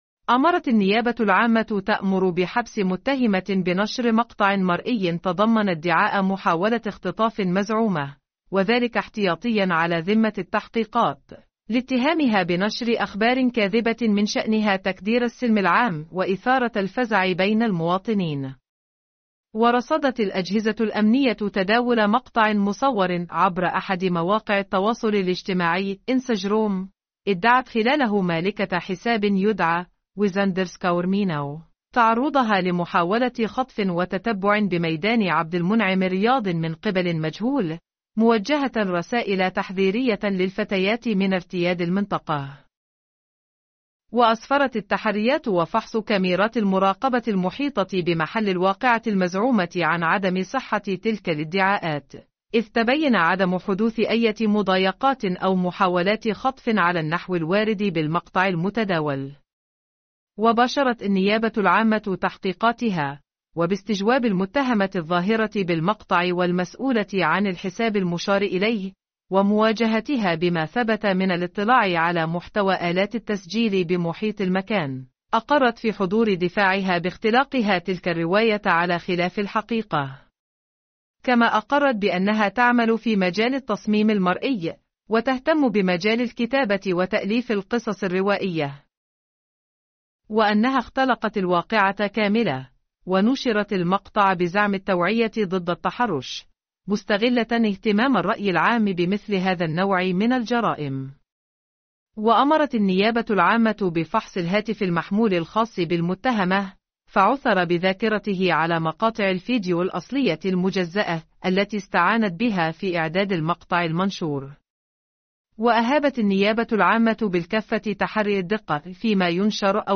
نشرة قضائية صوتية..